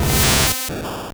Cri de Chrysacier dans Pokémon Or et Argent.